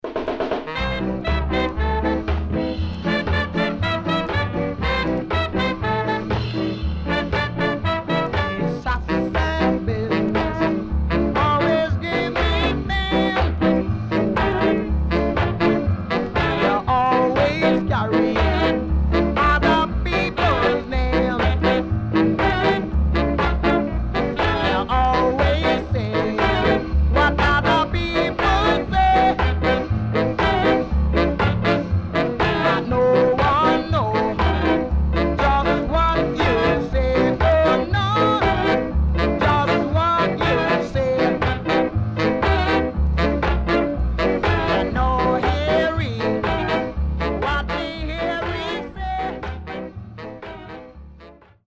Great Ska Vocal.W-Side Great!!